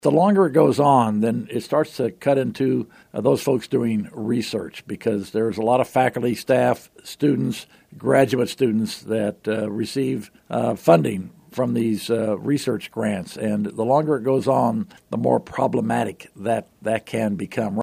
While it isn’t impacting students borrowing federal financial aid, K-State President Richard Myers, a guest on KMAN’s In Focus Monday, said it has the potential to impact research conducted at the university.